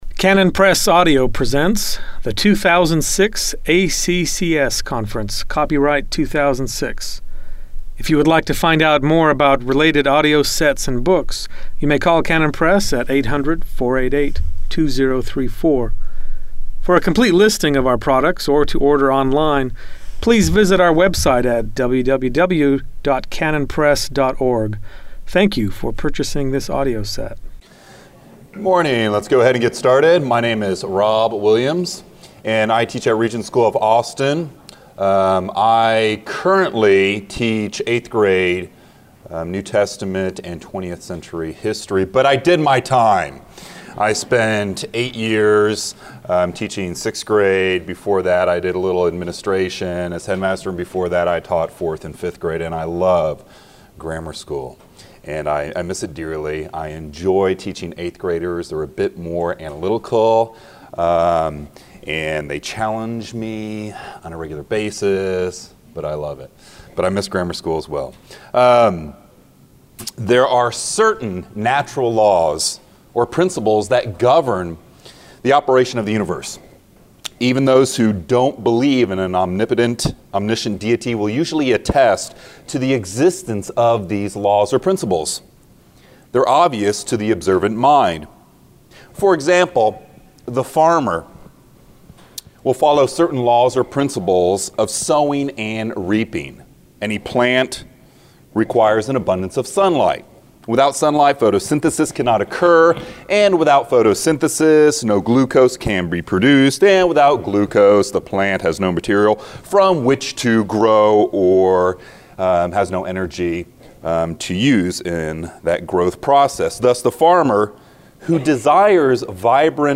2006 Workshop Talk | 0:58:30 | All Grade Levels, Math